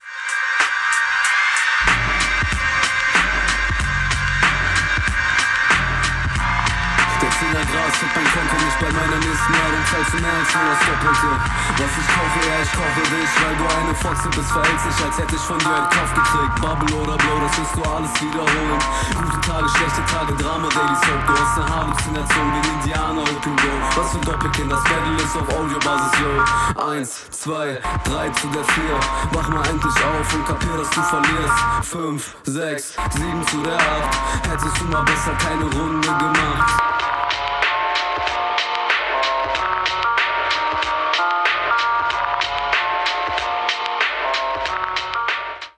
Sehr souverän, kommst auf diesen Beat besser als auf deinen eigenen, würde ich behaupten.